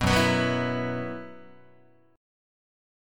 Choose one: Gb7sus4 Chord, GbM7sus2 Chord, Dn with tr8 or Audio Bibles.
Gb7sus4 Chord